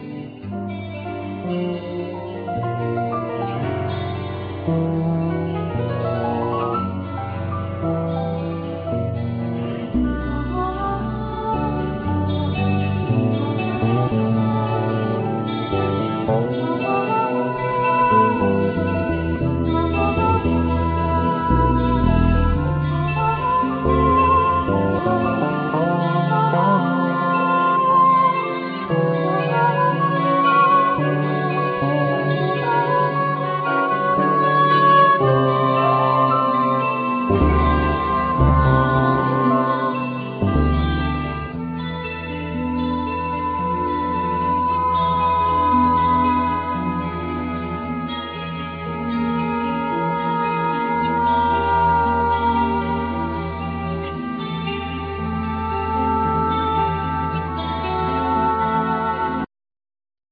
16 string acoustic guitar,El.guitar,Vocals
5 string electric bass
Soprano Saxophone
Piano
1st Violin